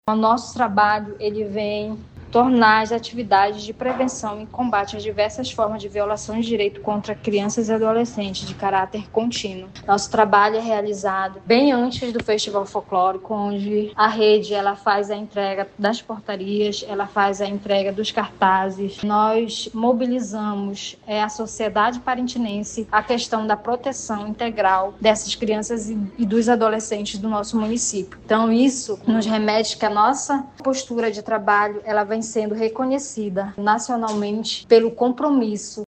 SONORA-PARINTINS.mp3